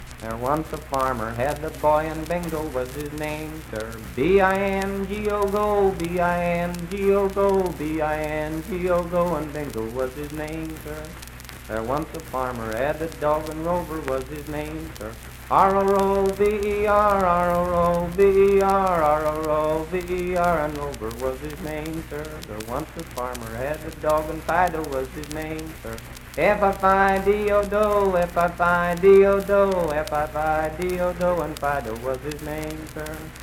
Unaccompanied vocal performance
Children's Songs, Dance, Game, and Party Songs
Voice (sung)
Spencer (W. Va.), Roane County (W. Va.)